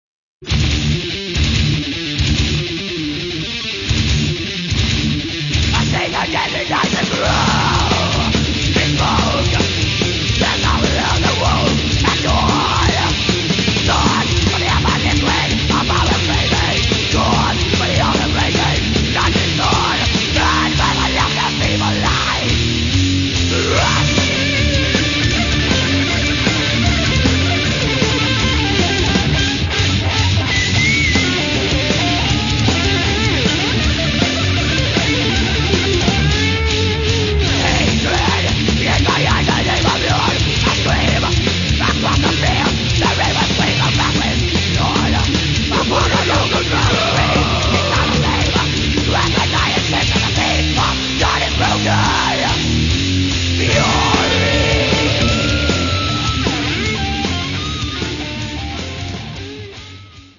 Все файлы размещены с качеством 32 Кбит/с, 22 кГц, моно